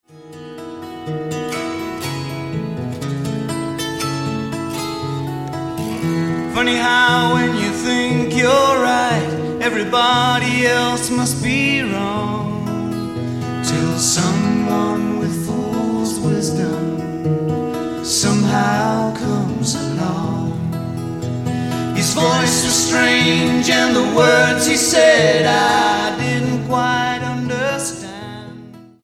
STYLE: Jesus Music
three bonus live tracks recorded in California